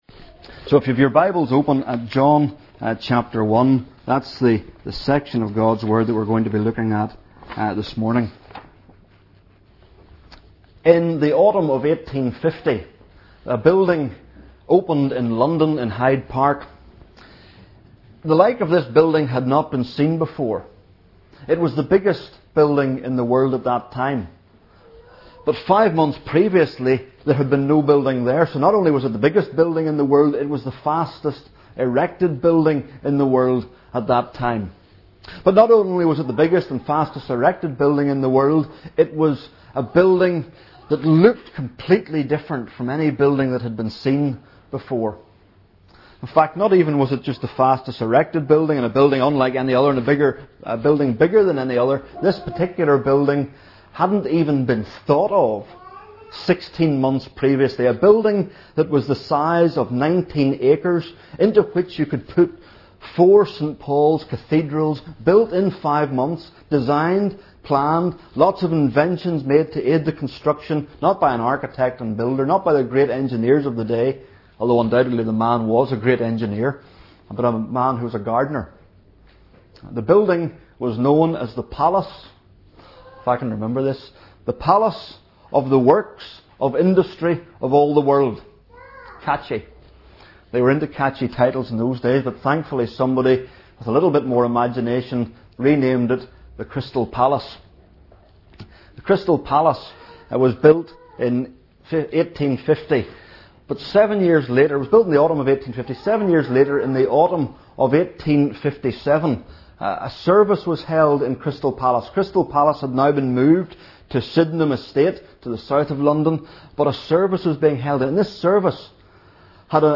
Behold the Lamb of God - John 1:29 | Single Sermons, John | new life fellowship